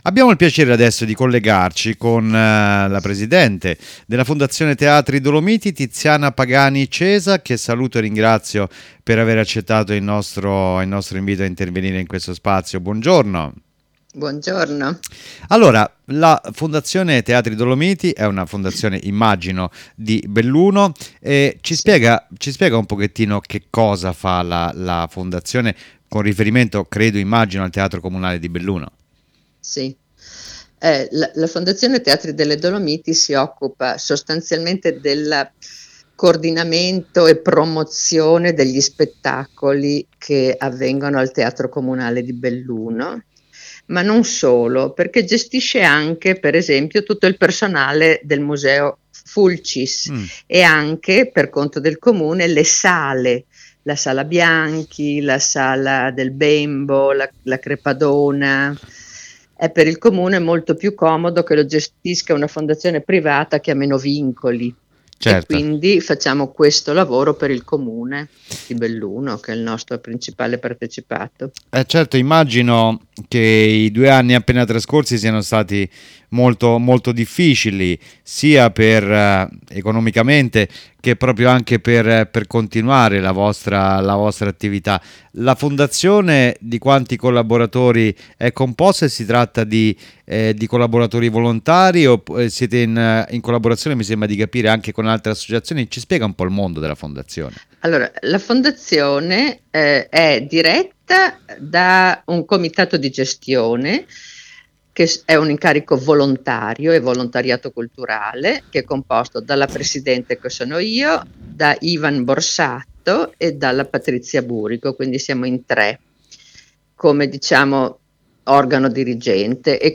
IERI ALLA RADIO